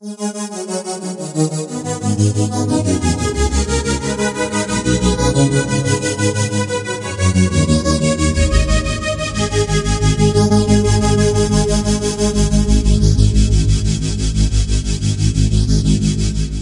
描述：鸭合成器循环。
声道立体声